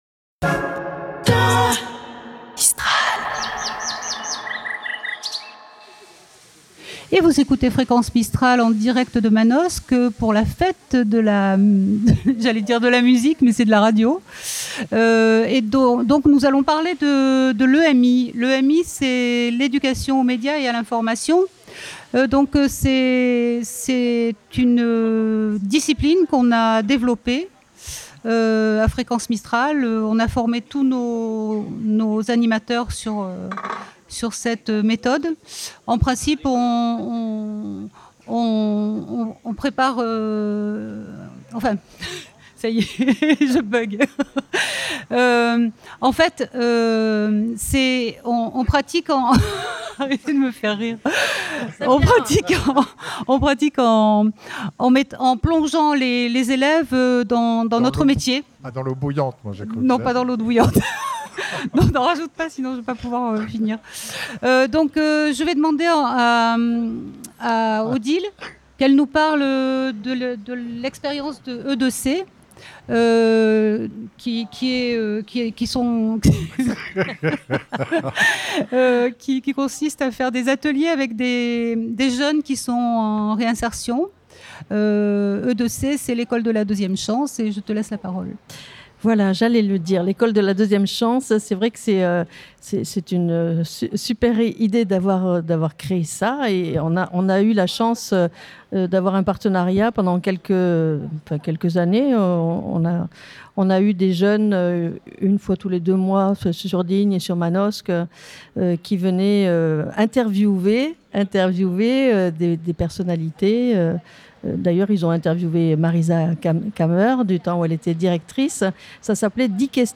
A cette occasion, toute l'équipe de Fréquence Mistral s'est retrouvée afin de vous proposer un plateau délocalisé en direct sur toute la journée sur Manosque. Retrouvez ci-dessous la rediffusion de la discussions autours de l'Education aux Médias et à l'Information : Fete de la Radio 2025 - EMI.mp3 (40.23 Mo)